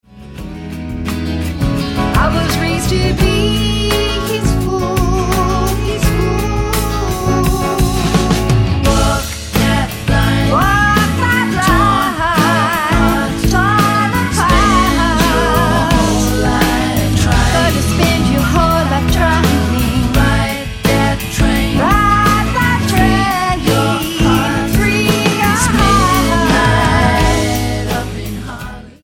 STYLE: Rock
puts in a fine vocal performance